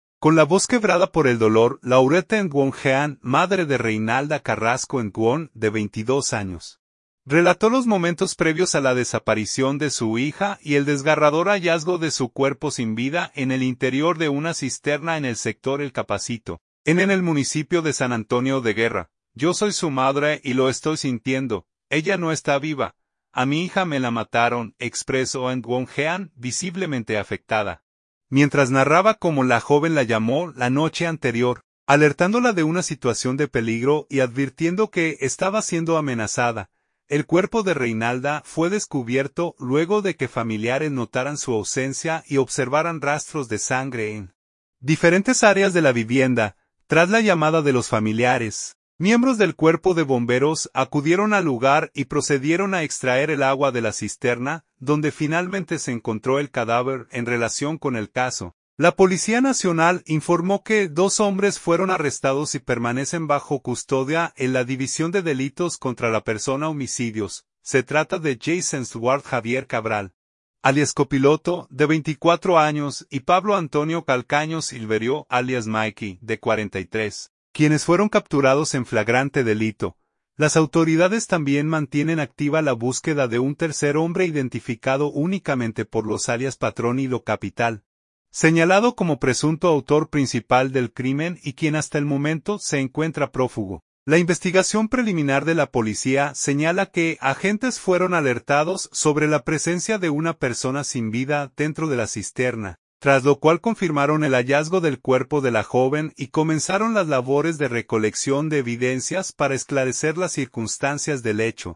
Madre entre lágrimas: “Me la mataron” el dolor tras hallar a hija sin vida en una cisterna
Con la voz quebrada por el dolor